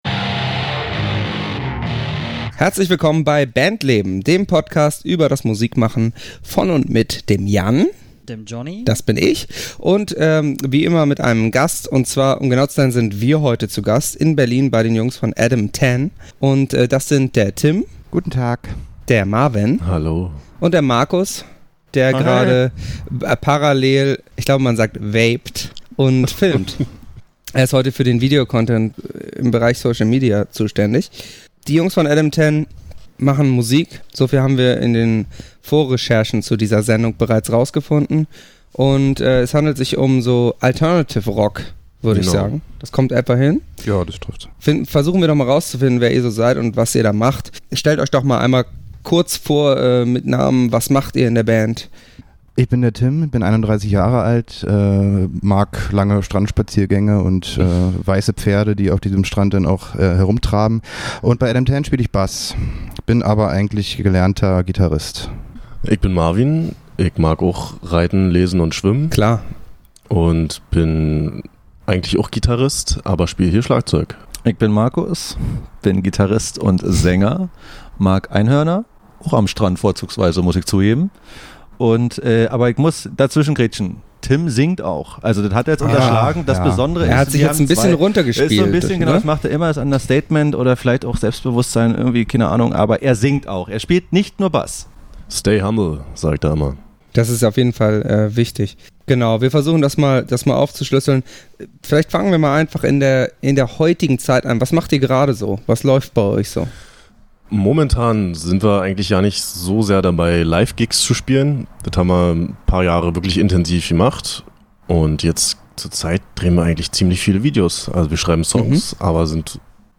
August 2019 Nächste Episode download Beschreibung Teilen Abonnieren Wir waren bei Adam Tan in Berlin zu Gast und haben uns mit ihnen unterhalten, über dies, über das und den Stand der Musikindustrie im Großen und Ganzen. Eine etwas experimentelle Folge mit drei Gästen, vier Mikros, einem sehr kleinen, aber dafür sehr heißem Raum, und viel Abschweifen. Von Take Jenes bis ins Olympiastadion, warum Berlin schwierig ist und Johnny Deathshadow Konzerte gefährlich.